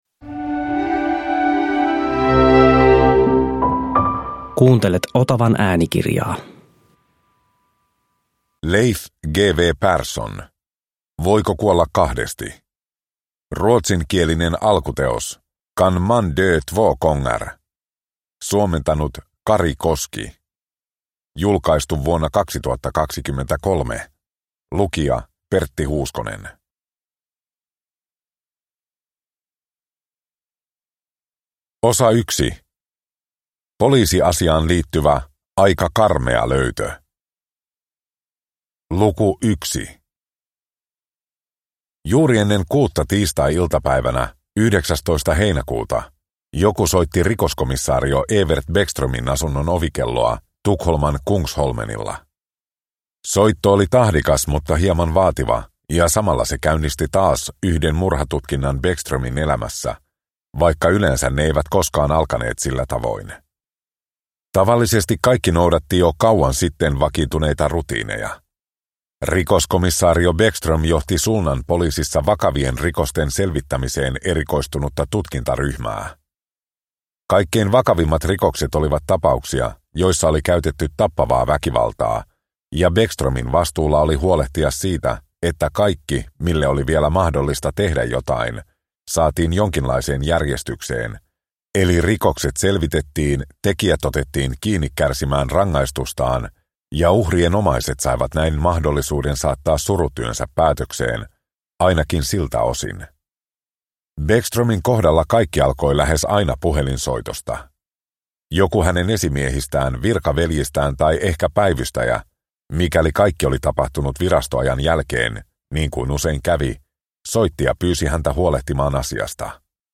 Voiko kuolla kahdesti – Ljudbok – Laddas ner